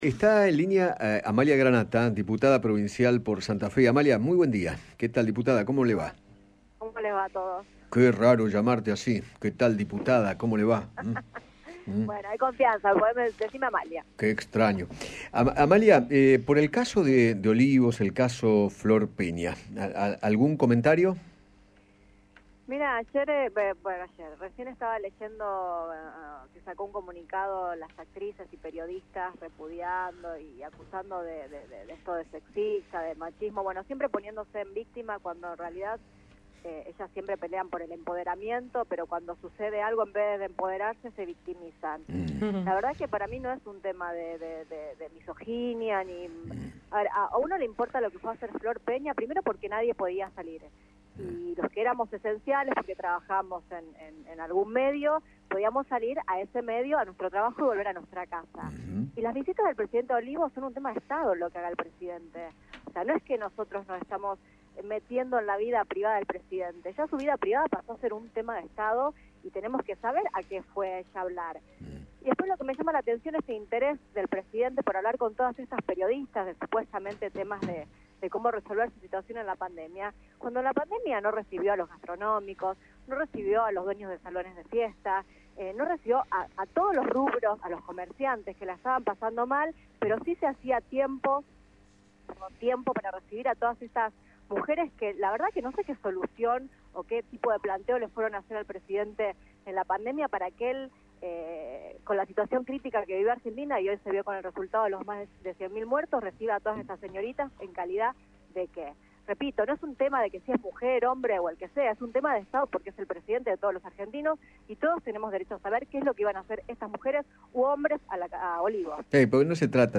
Amalia Granata, diputada provincial por Santa Fe, habló con Eduardo Feinmann acerca de los dichos de Florencia Peña en referencia a su visita a la Quinta de Olivos y criticó el accionar de Alberto Fernández en plena cuarentena obligatoria.